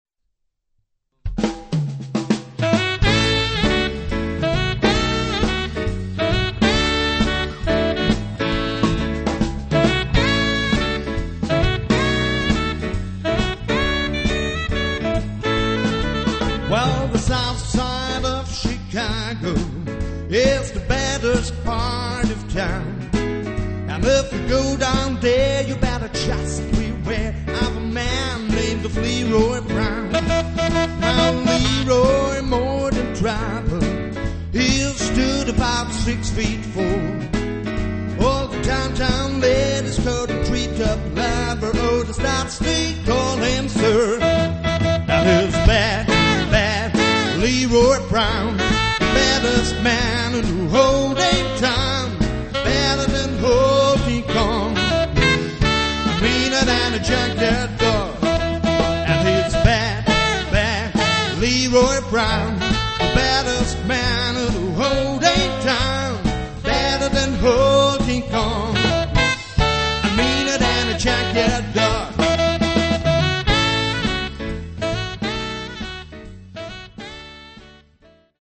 Swing  -  Latin  -  Pop  -  Soul  -  Rock  -  Tanzmusik
Sänger, Saxophon, Piano/Keyboards, Bass und Schlagzeug